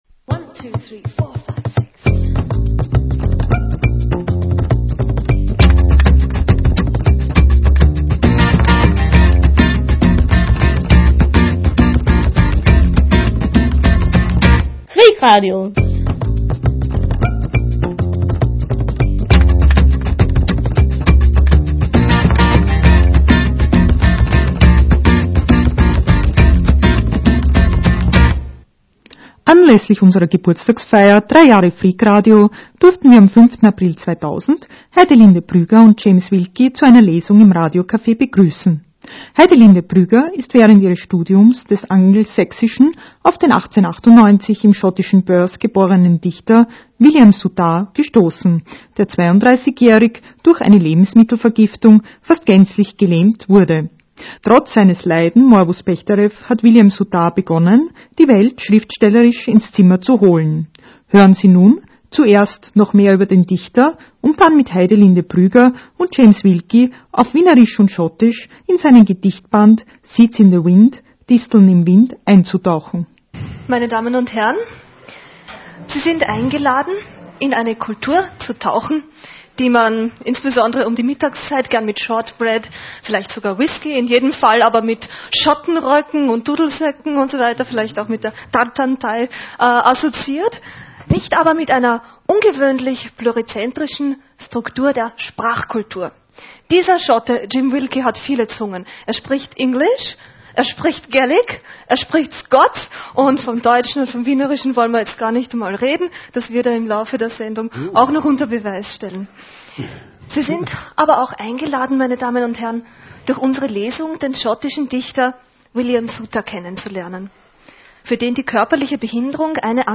Lesung
Schottische Gedichte von William Soutar, mit Übersetzung ins Wienerische, aufgenommen anlässlich der Geburtstagsfeier zum 3. Jahrestag von Freak-Radio.